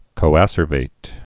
(kō-ăsər-vāt, kōə-sûrvĭt)